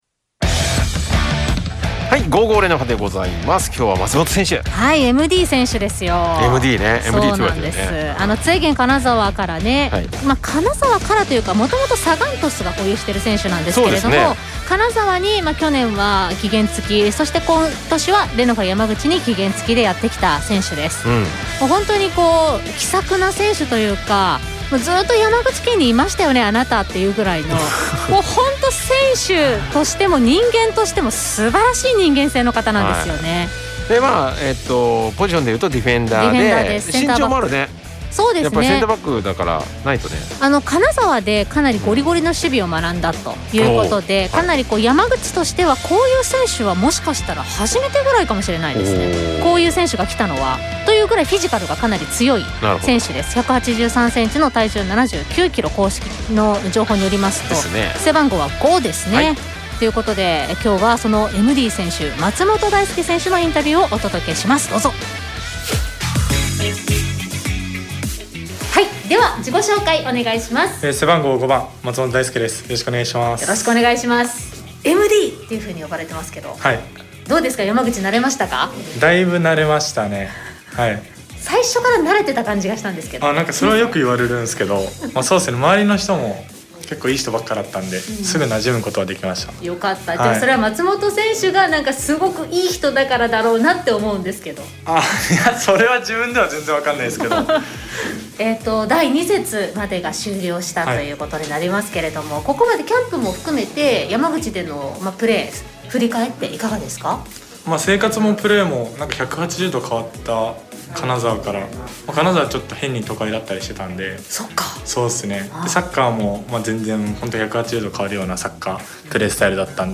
リポーター